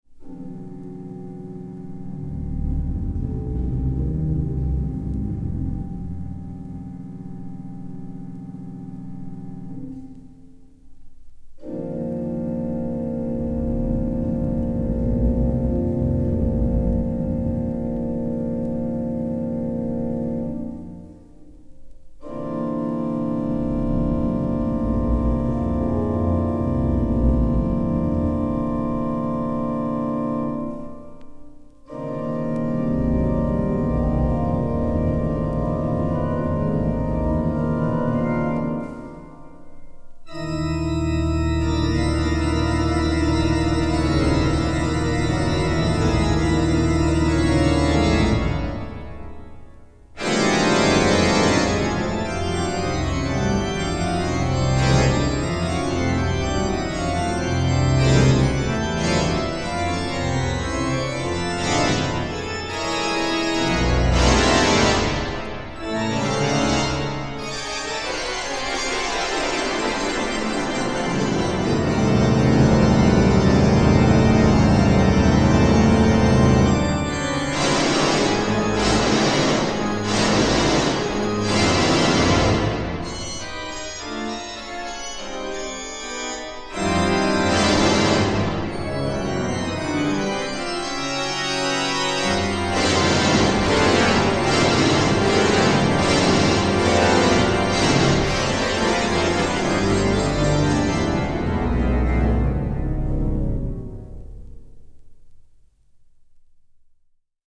für Englischhorn und Orgel